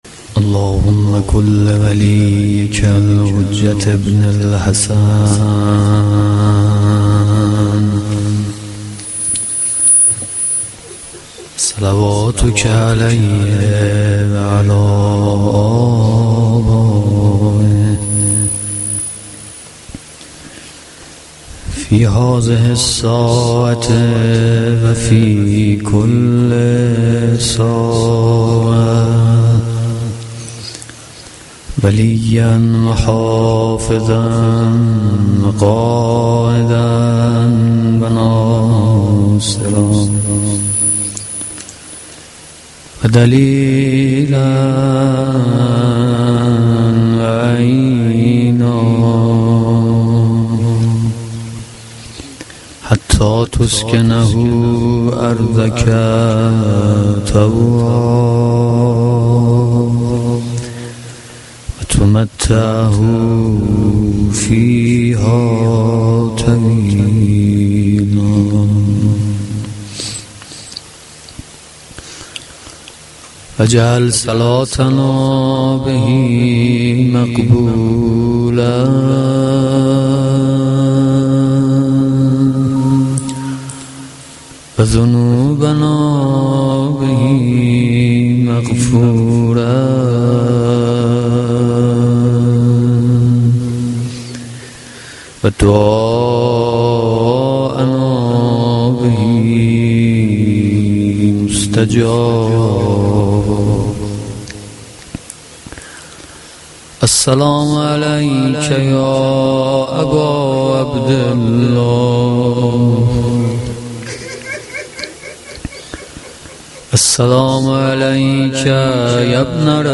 مداحی
Shab-1-Moharam-2.mp3